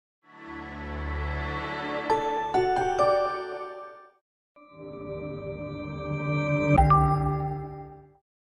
Звук загрузки ОС Harmony